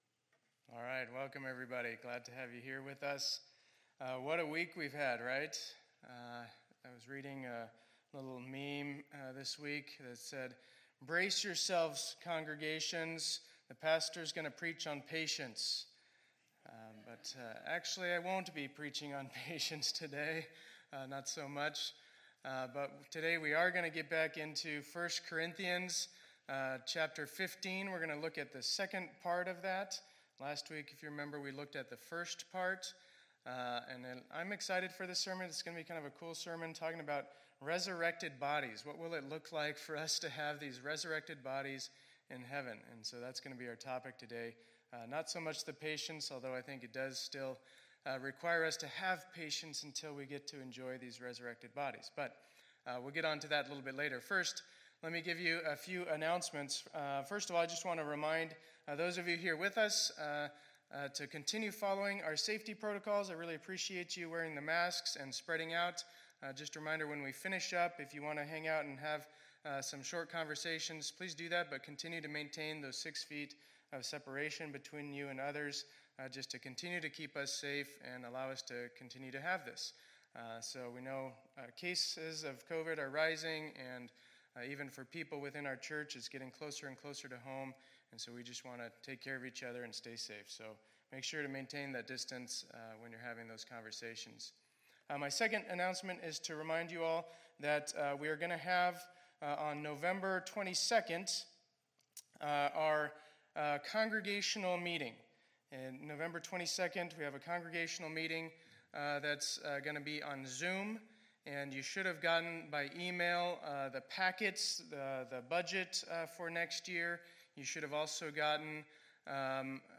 2020-11-08 Sunday Service